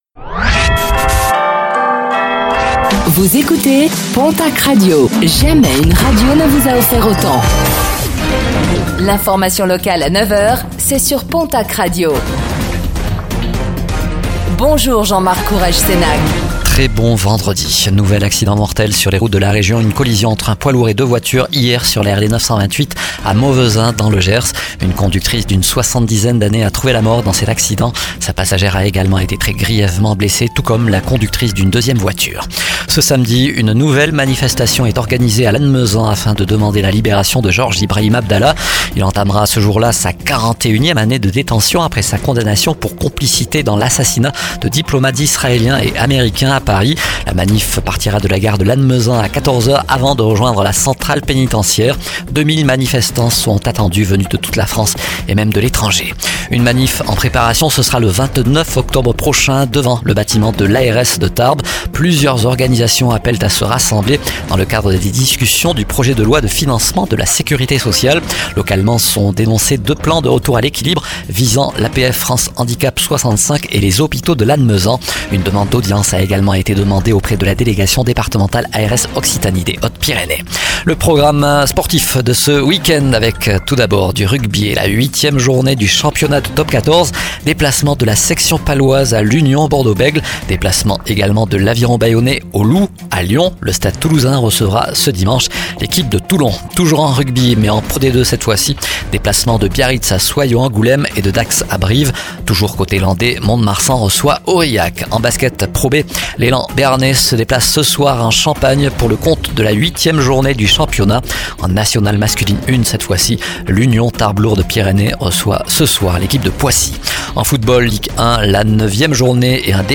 Infos | Vendredi 25 octobre 2024